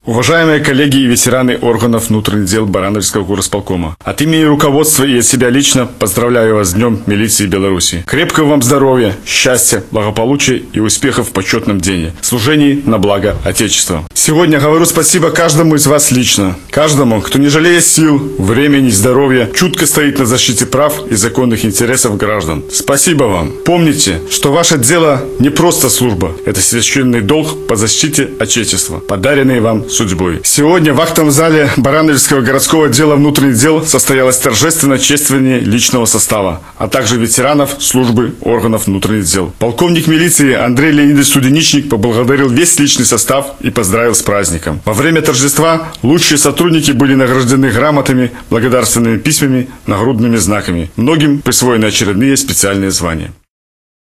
Сегодня торжественное мероприятие собрало в актовом зале ОВД Барановичского горисполкома представителей органов внутренних дел.